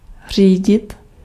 Ääntäminen
IPA : /draɪv/ IPA : /dɹaɪv/